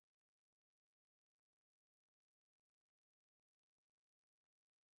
I wanted to generate an audio file—the sound of a system hesitating. I modeled it on the Barkhausen effect (the snapping of magnetic domains under stress). It’s not smooth, and it’s not silent.
It’s raw, jagged, and has that “thicker” texture we’re talking about.